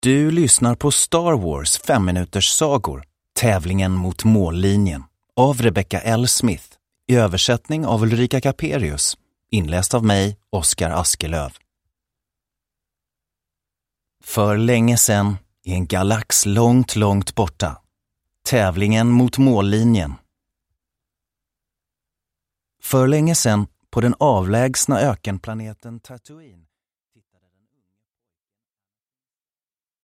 Uppläsare: